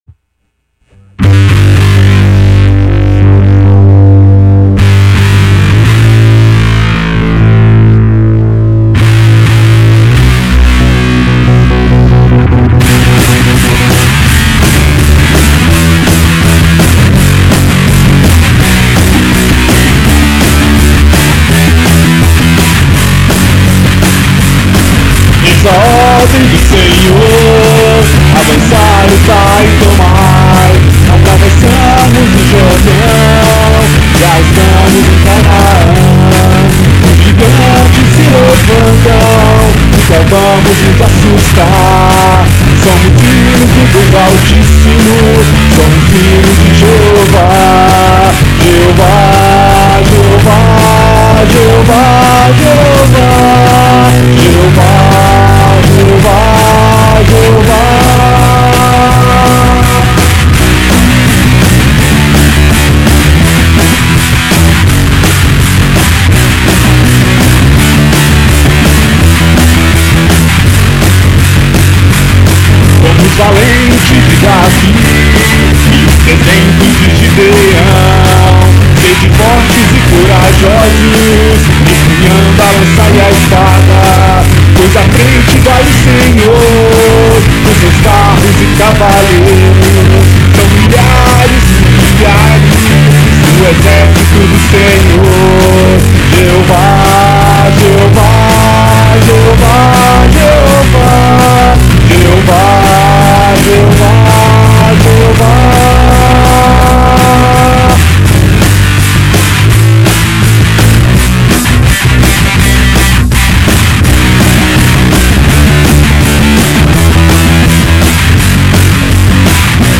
Voz e instrumentos